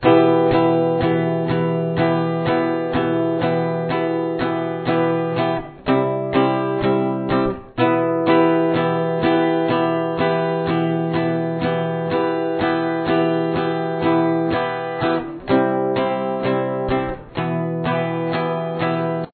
Verse
Rhythm